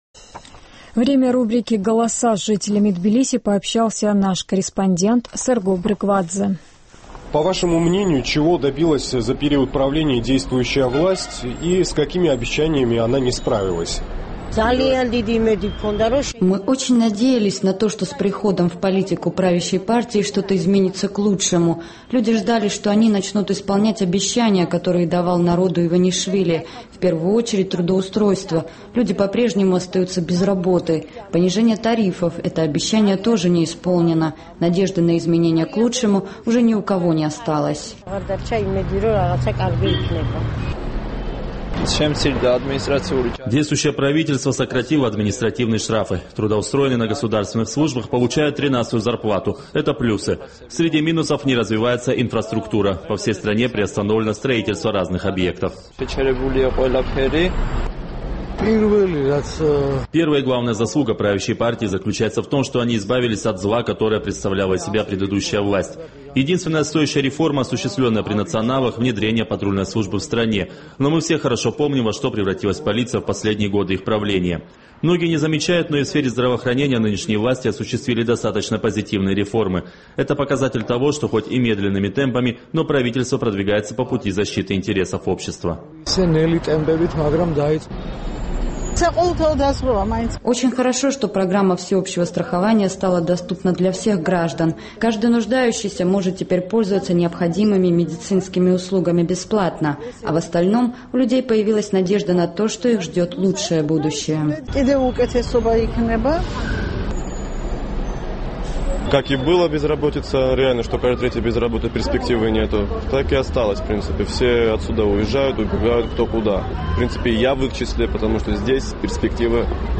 Каких успехов достигла правящая партия за этот период и с какими обещаниями не справилась? Наш корреспондент интересовался мнением жителей Тбилиси по этим вопросам.